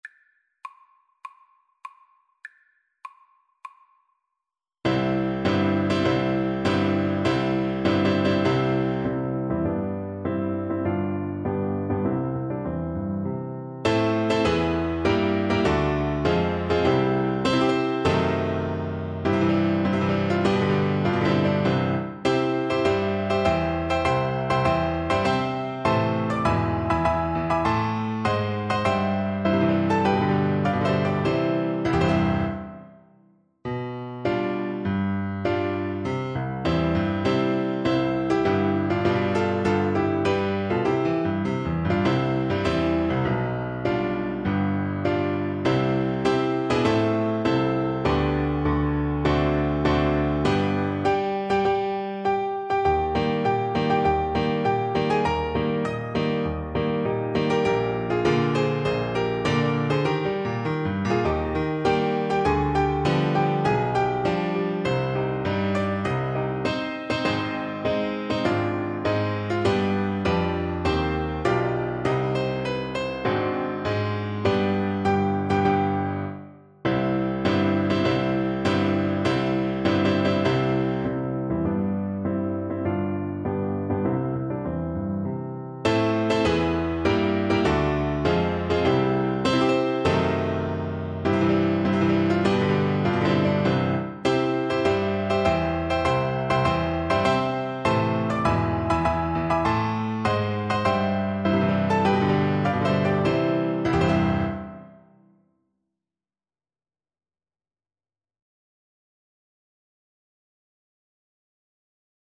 2/2 (View more 2/2 Music)
Marcial
Traditional (View more Traditional Voice Music)